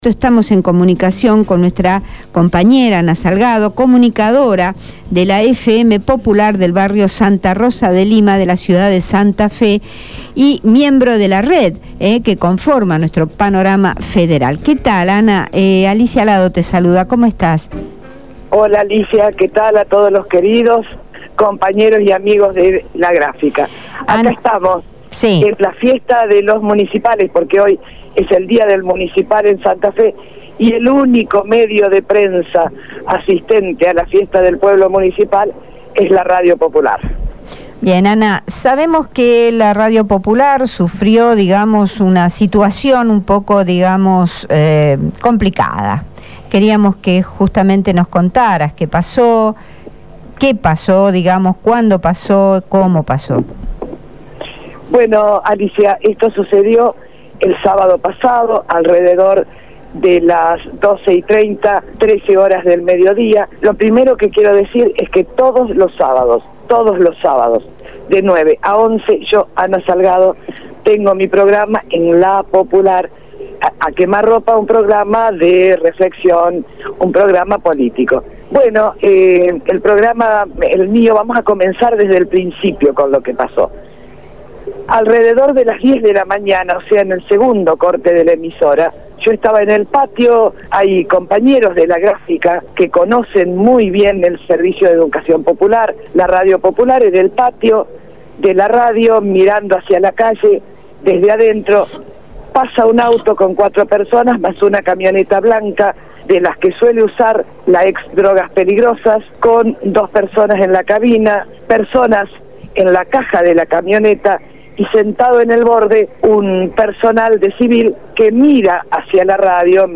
comunicadora de la radio FM Popular de la Ciudad de Santa Fe habló con el programa Abramos la Boca.